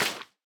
sounds / step / wet_grass4.ogg
wet_grass4.ogg